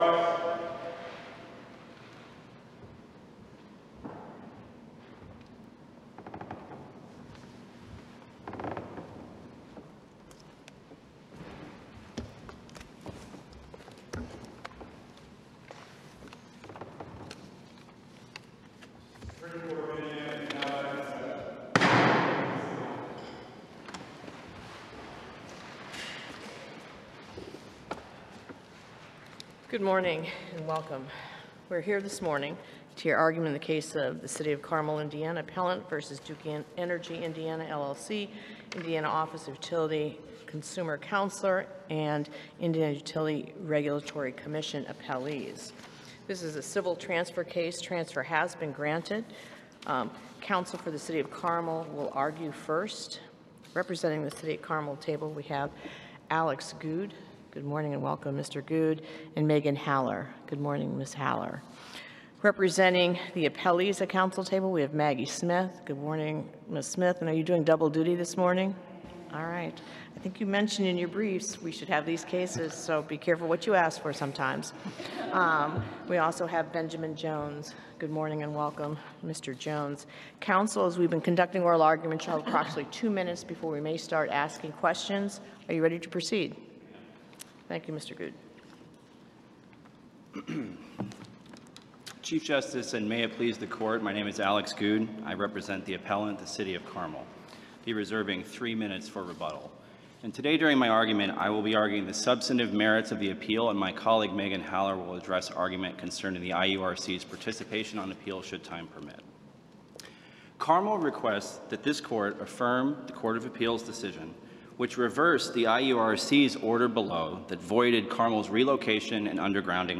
Supreme Court hears arguments in ordinance disputes — Indiana Utility Report
The Indiana Supreme Court held oral arguments in two appeals last week, both involving Duke Energy Indiana.
Counsel for the City of Carmel argued that local municipalities have the right to regulate utility operations within their jurisdictions.